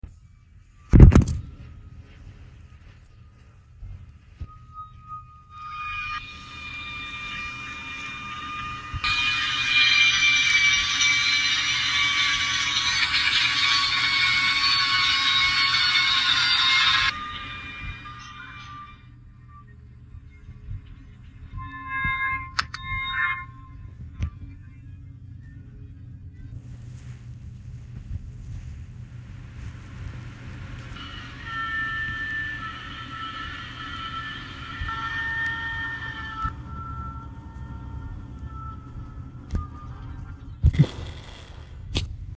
Musica di sottofondo